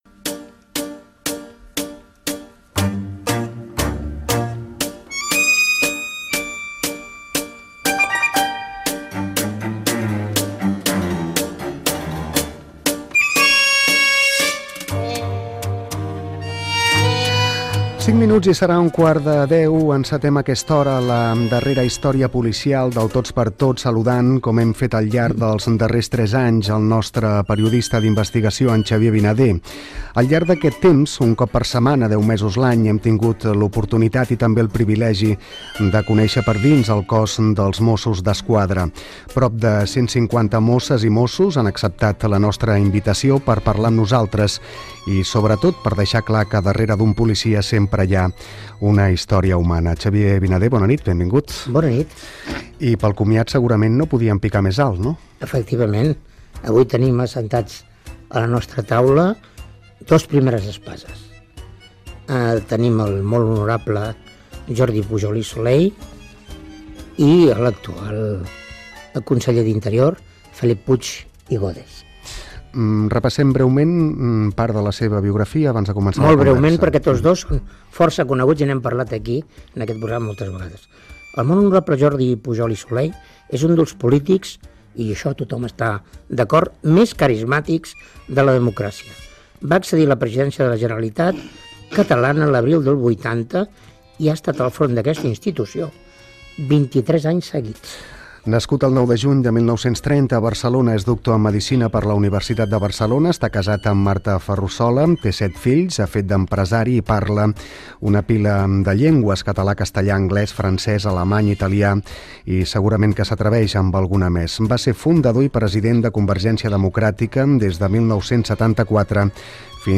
Convidats: el president de la Generalitat Jordi Pujol i el Conseller d'Interior Felip Puig
Fragment extret de l'arxiu sonor de COM Ràdio.